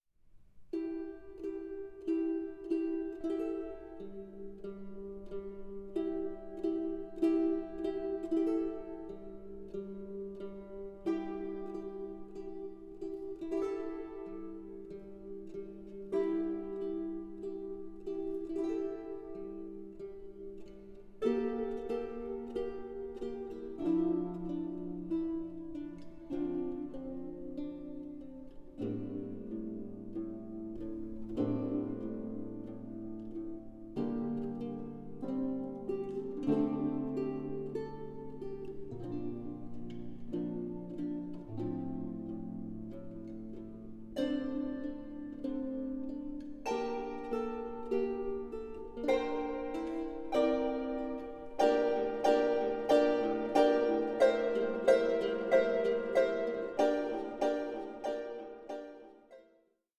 MELANCHOLIC SOLACE FOR TROUBLED TIMES